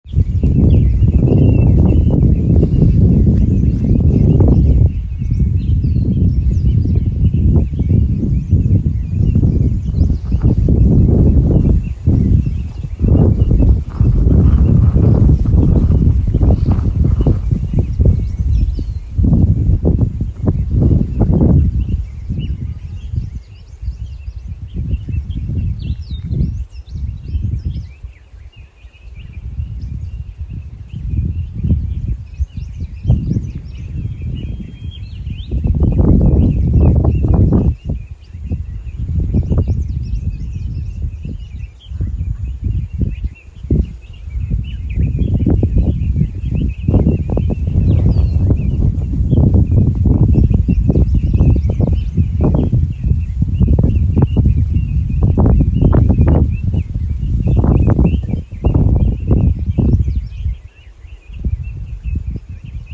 大围山浏阳河源头鸟叫声
大围山国家森林公园浏阳河源头鸟鸣声